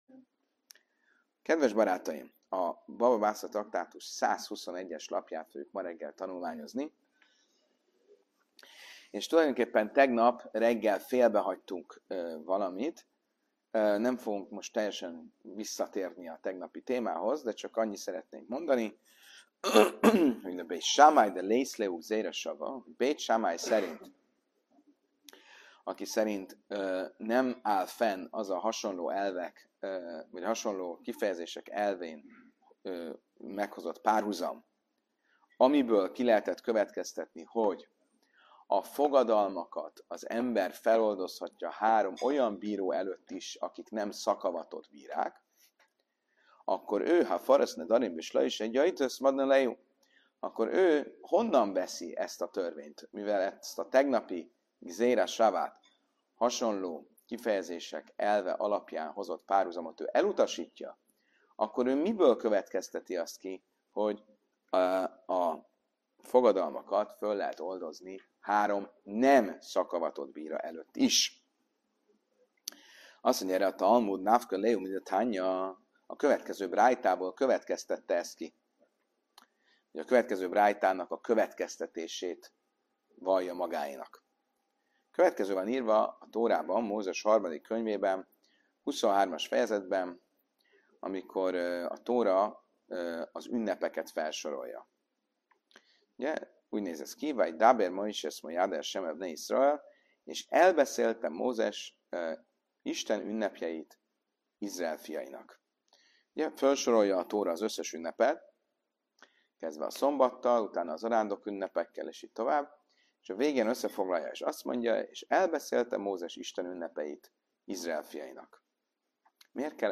Helyszín: Óbudai Zsinagóga (1036. Budapest, Lajos u. 163.)